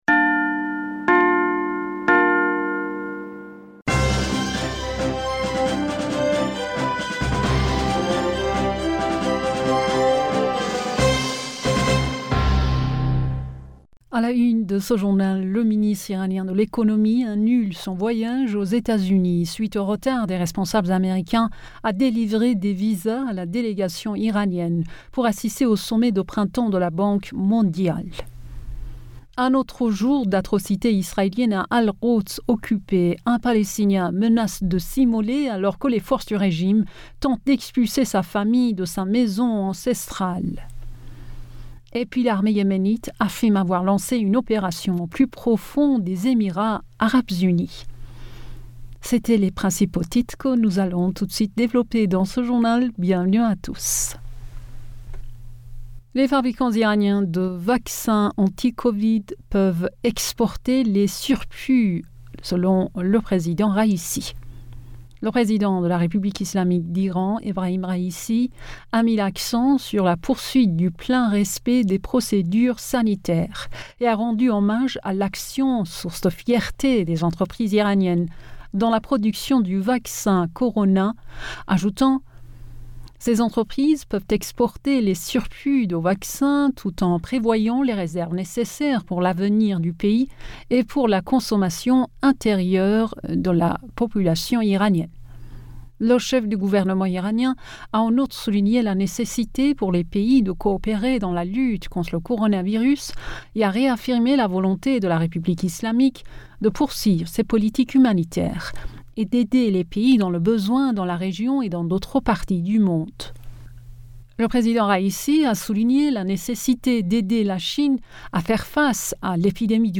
Bulletin d'information Du 17 Avril 2022